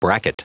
Prononciation du mot bracket en anglais (fichier audio)
Prononciation du mot : bracket